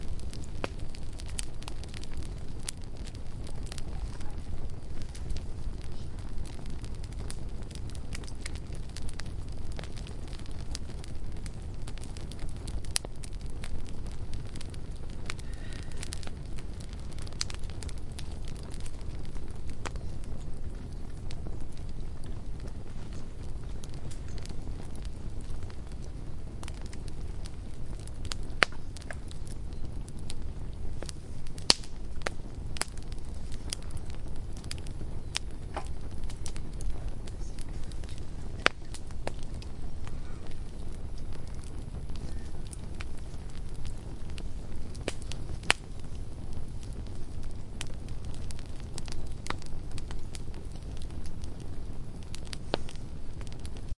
篝火晚会
描述：在Longoz森林与朋友一起露营时记录。围着火堆，有10个人坐在露营椅上，我告诉他们要保持安静。
在Audition中应用多频带压缩器，增益为10db。
Tag: 噼啪声 火焰 营火 火花 裂纹 烧伤 火灾 自然 火焰 燃烧